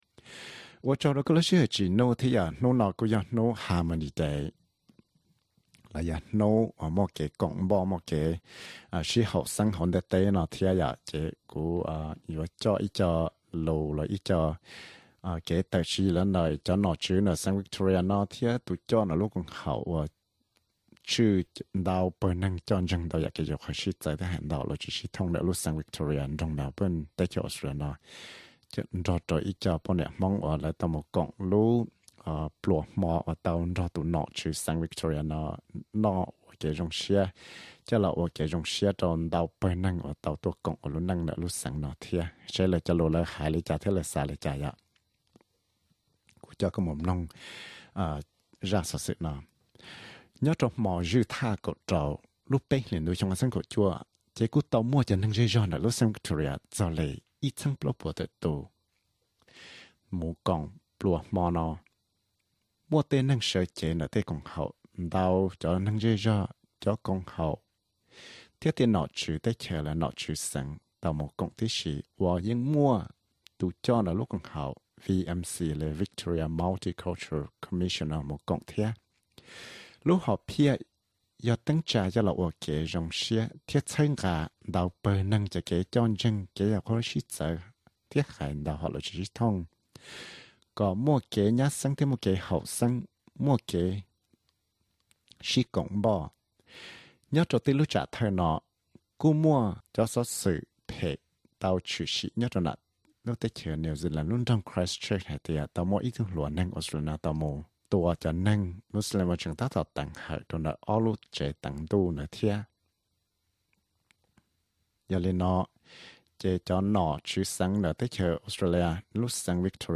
Hmong Australia Festival Inc at VIC Premier gala dinner-SBS Hmong Source: SBS Hmong